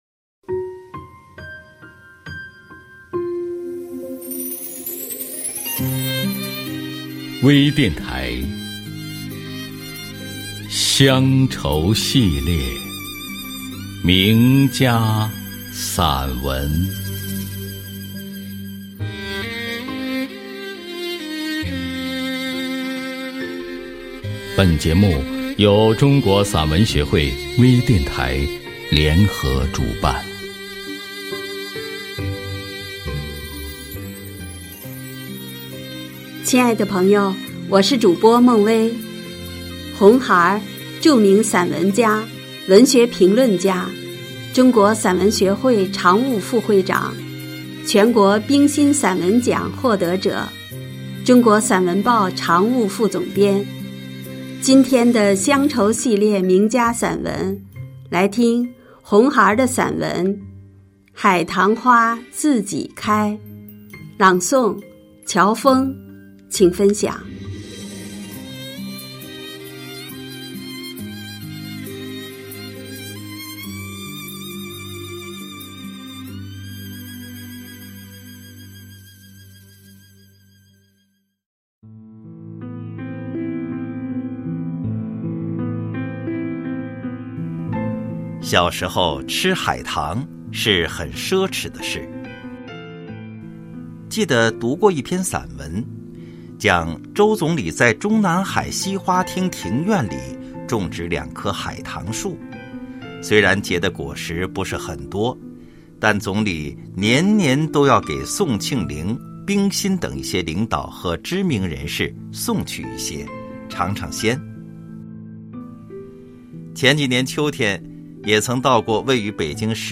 多彩美文 专业诵读 精良制作 精彩呈现 * D. q k& h: a; ~$ f! d4 N6 T a/ V1 i1 }, i& O9 X- x' O& c) y( z $ @9 t( K6 [8 y!